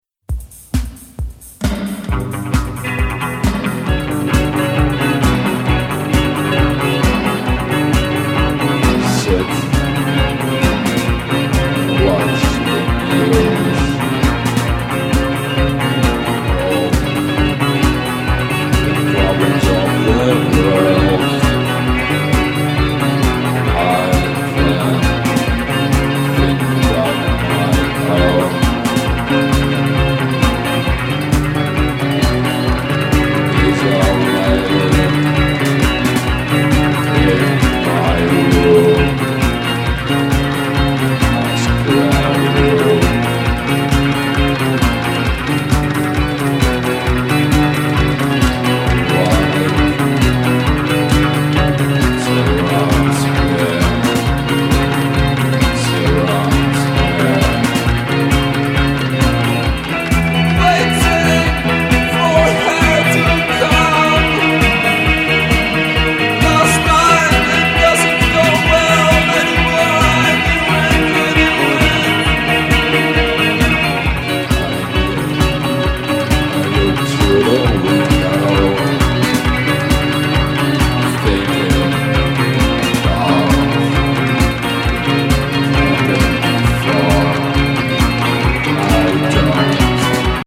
Synthesizers
dark wave sounds